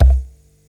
07_Kick_08_SP.wav